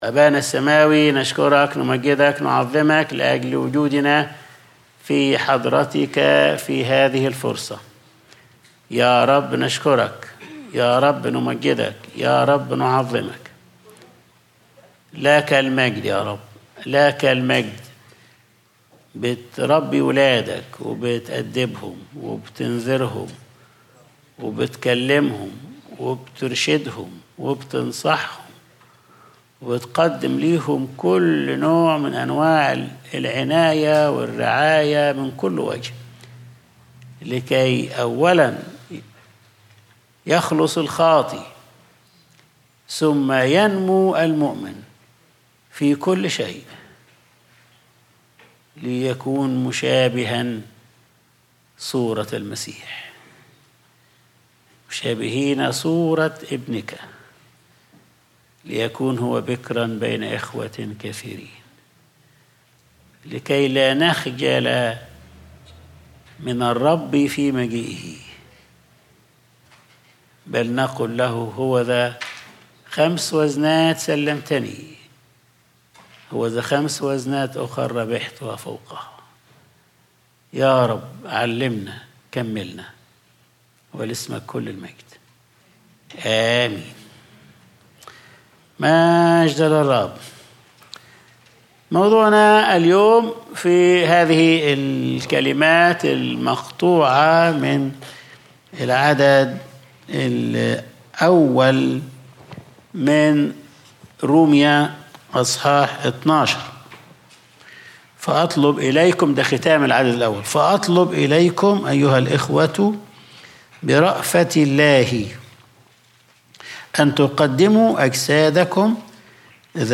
Sunday Service | عبادتكم العقلية